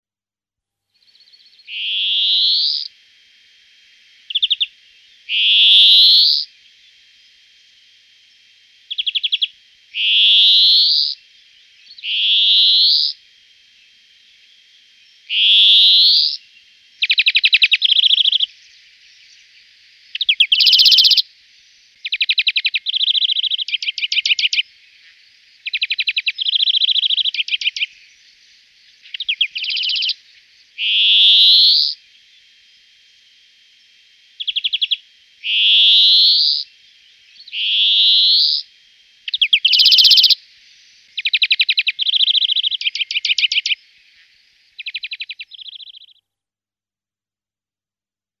Chant :
Verdier d'Europe
Le verdier émet un rapide gazouillis en vol " djururut ". On peut aussi entendre un doux " tsooeet ", et un court " chip-chip " quand il est posé ou en groupes.
C'est une série de phrases gazouillées en " dzweeeee " prolongés, durs et nasillards.
Extrait du CD " Les oiseaux d'Europe" de J.C. Roché ED : Sitelle
88Greenfinch.mp3